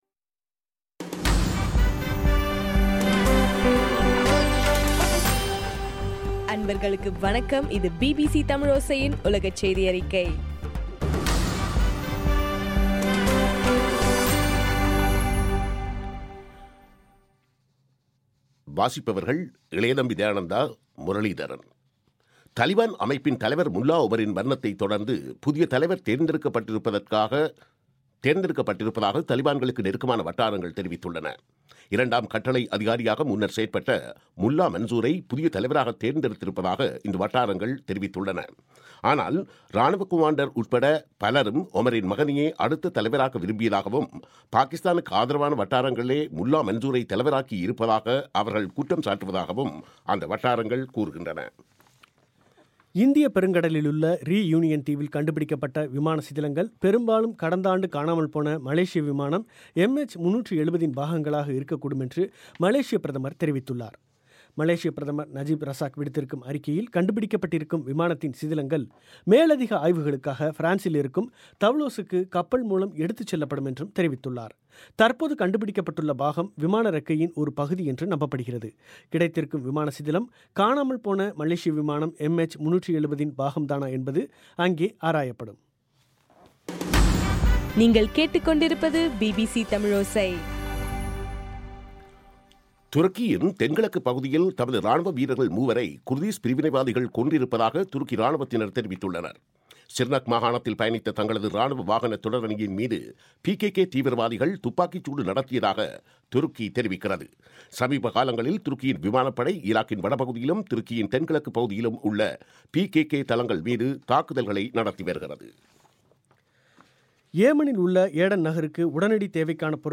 ஜூலை 30 பிபிசியின் உலகச் செய்திகள்